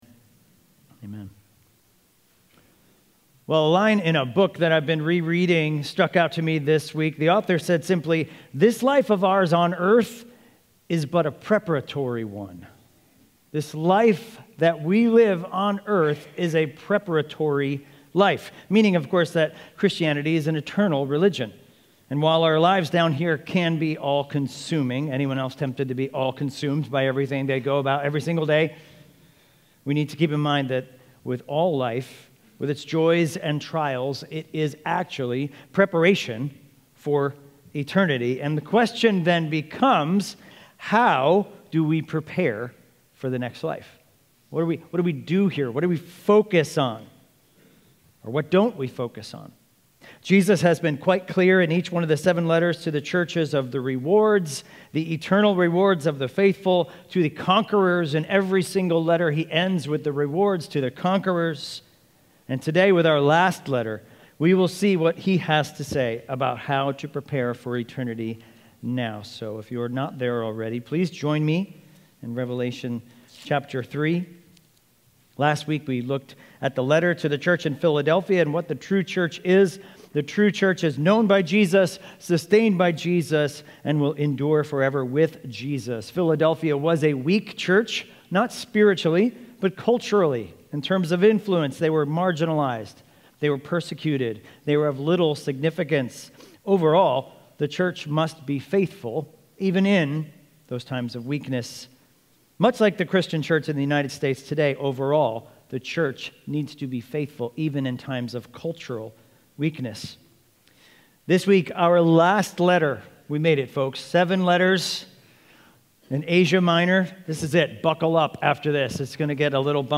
Expositional preaching series through the book of Revelation.